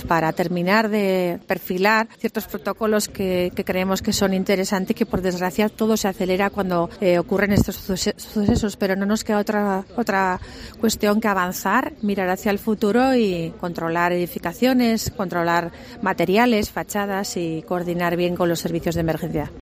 La alcaldesa de Huesca, Lorena Orduna, habla de la revisión de protocolos de control de edificios